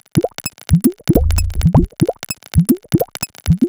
Wuobwuob2 130bpm.wav